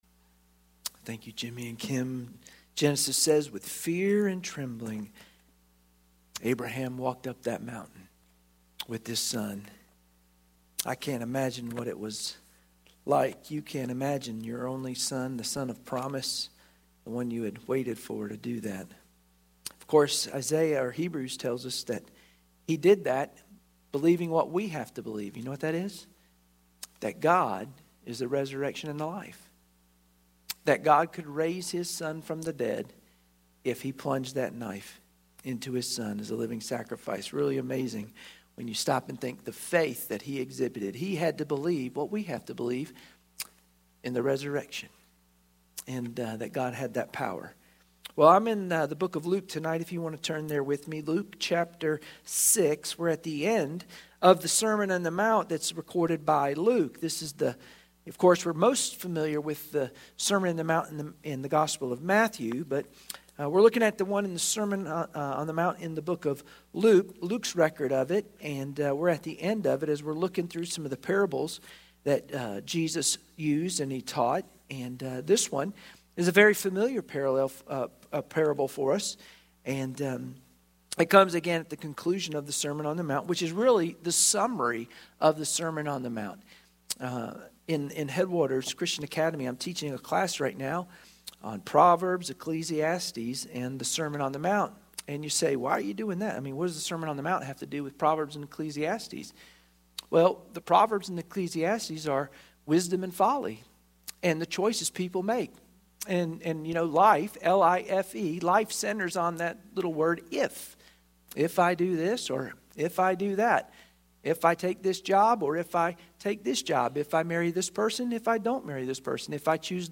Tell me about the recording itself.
Luke 6:46-49 Service Type: Sunday Evening Worship Share this